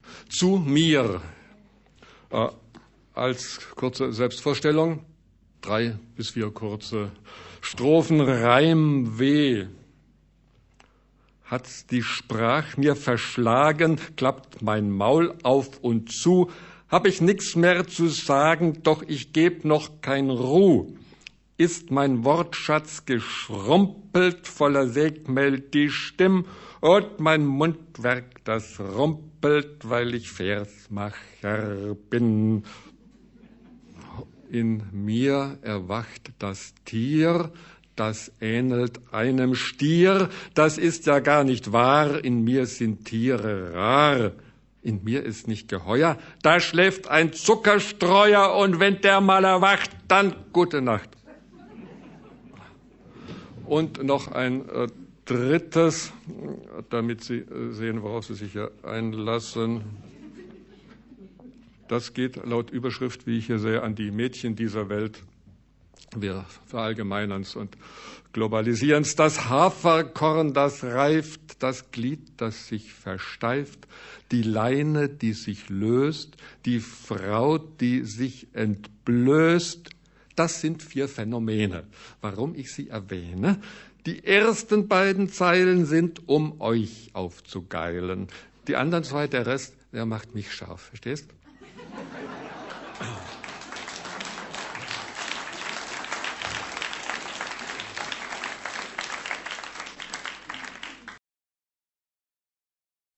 Gedichte
Gute Laune macht dem Hörer dieser CD nicht nur F.W. Bernsteins sanfter Bariton, mit dem er seine besten Gedichte der Welt vorträgt
mit Cello und Klavier für jene leichte, schwebende Atmosphäre